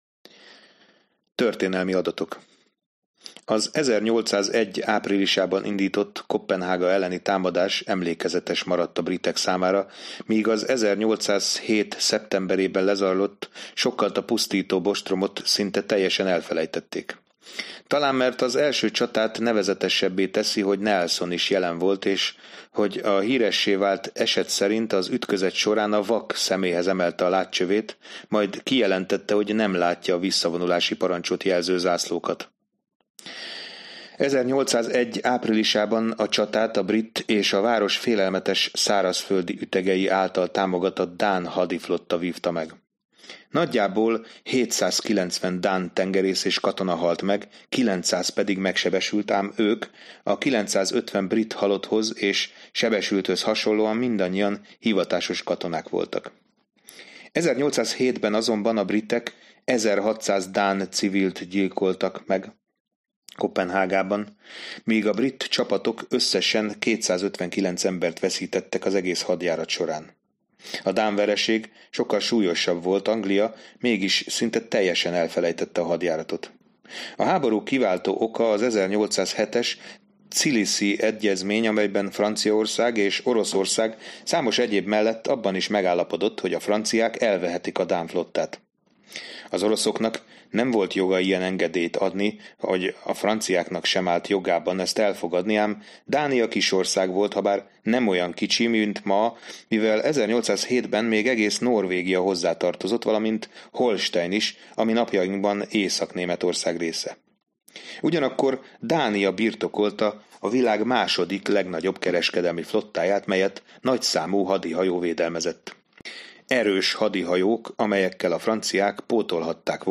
Bernard Cornwell – Sharpe zsákmánya Hangoskönyv A Sharpe sorozat 5. része Előadja